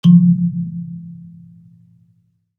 kalimba_bass-F2-ff.wav